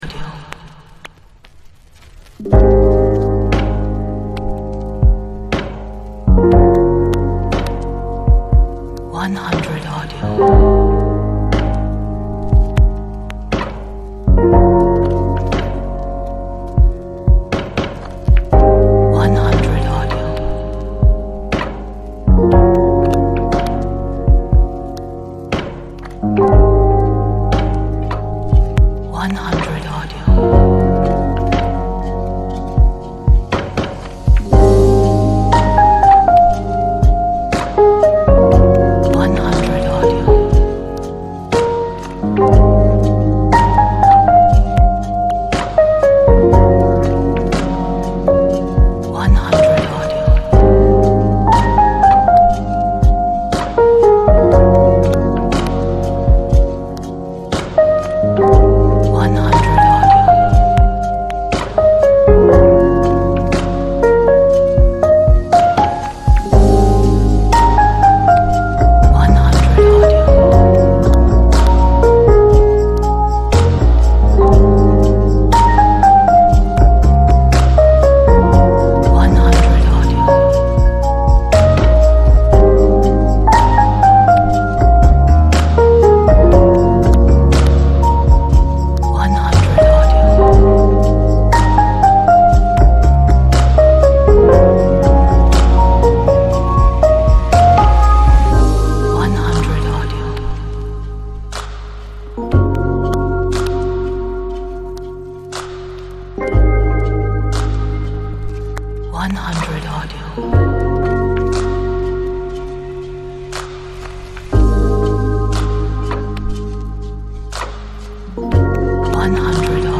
Mood of sadness and longing. But stylish.
这是首带点悲伤和渴望的情绪时尚音乐。